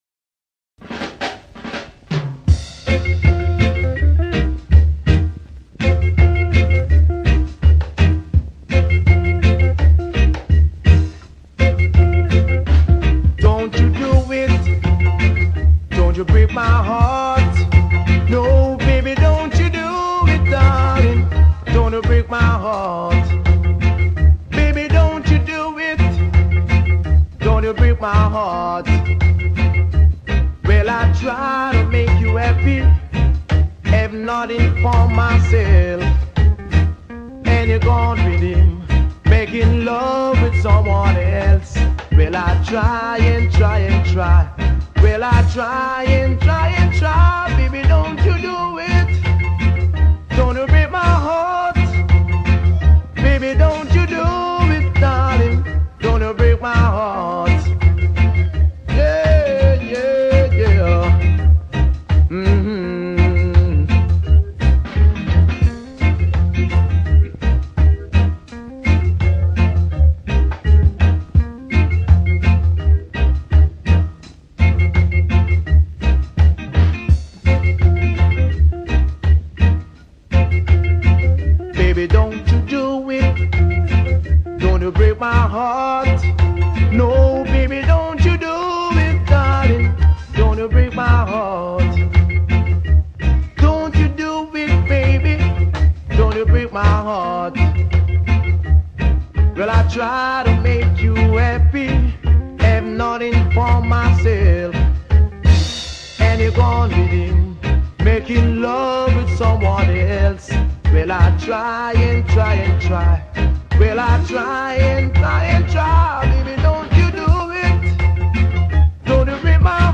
Strictly for the high-steppers!